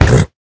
minecraft / sounds / mob / horse / hit3.ogg
hit3.ogg